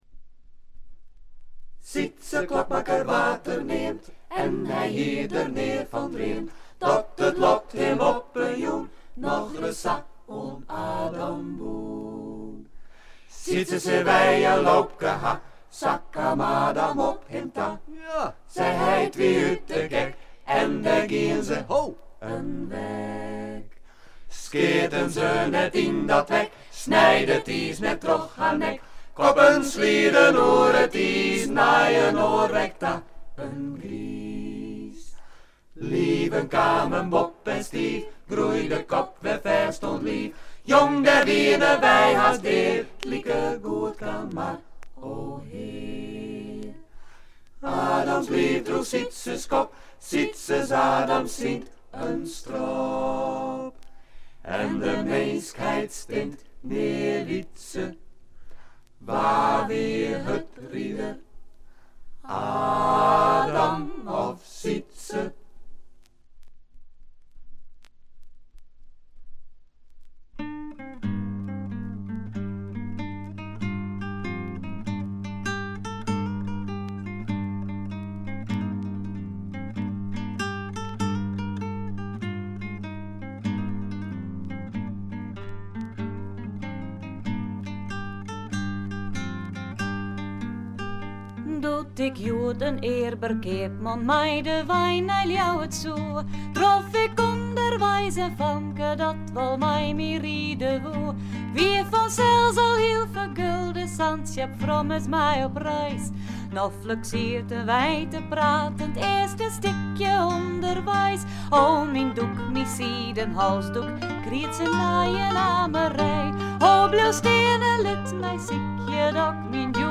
チリプチ少し。散発的なプツ音少し。
ダッチフォークの名バンド
試聴曲は現品からの取り込み音源です。